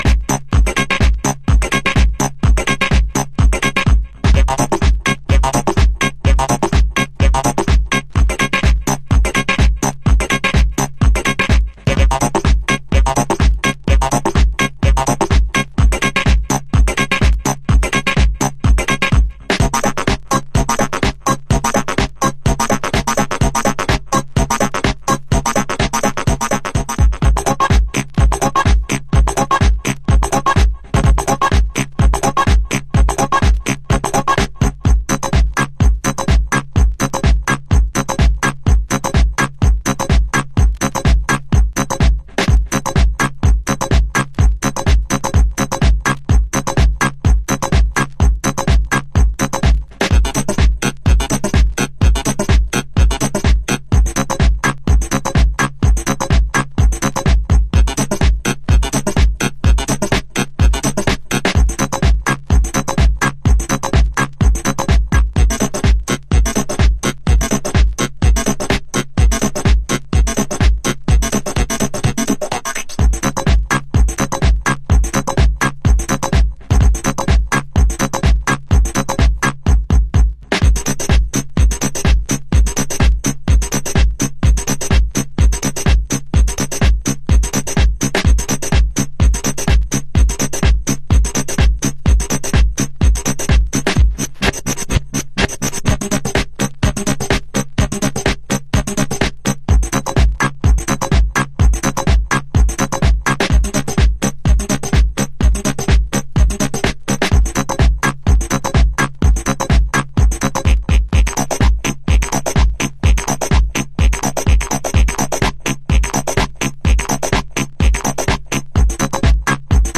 ディスコグルーヴも孕んだデジタル・カットアップテクノ。
House / Techno